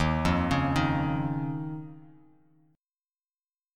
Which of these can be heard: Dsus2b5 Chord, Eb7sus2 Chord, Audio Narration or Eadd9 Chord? Eb7sus2 Chord